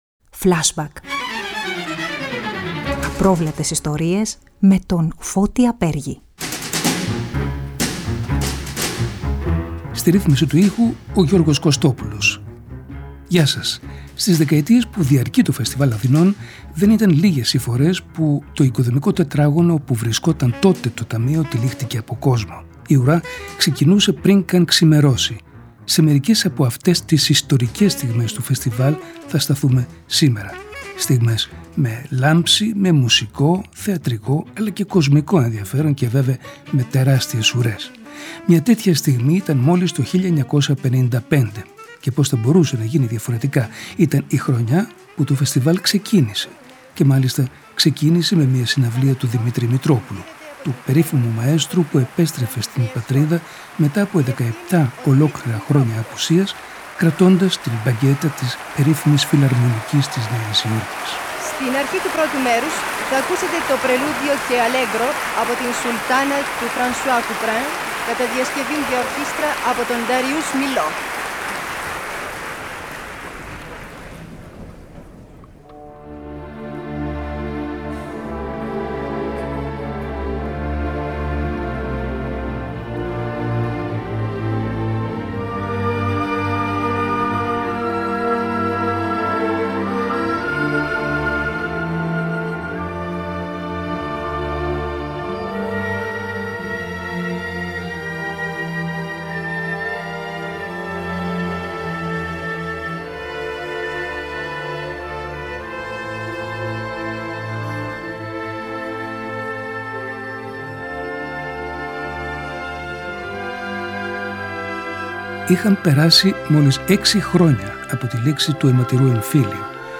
Ακούστε το από σήμερα, Παρασκευή 23 Ιουνίου, αποκλειστικά στο ERTecho, μαζί με αποσπάσματα από ιστορικές συναυλίες των πρώτων δεκαετιών του Φεστιβάλ Αθηνών στο ρωμαϊκό θέατρο.